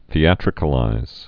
(thē-ătrĭ-kə-līz)